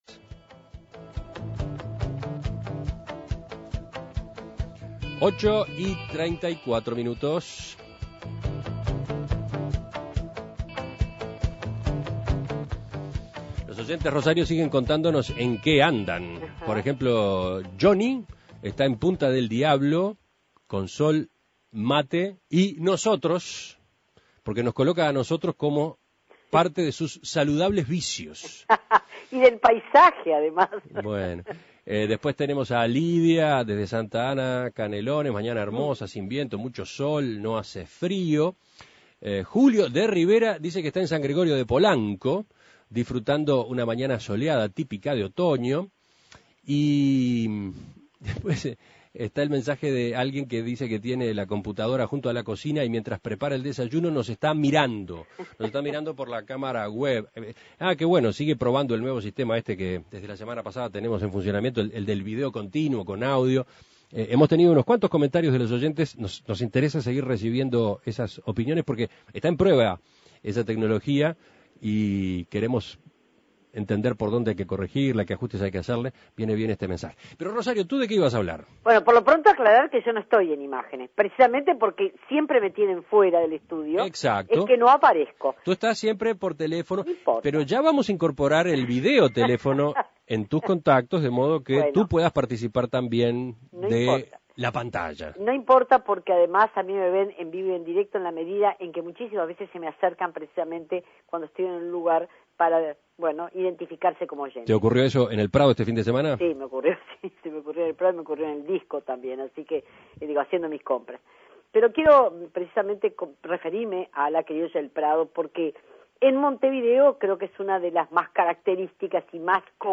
Visita a la Semana Criolla en el Prado